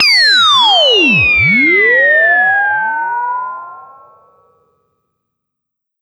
Ring Mod Fall 2.wav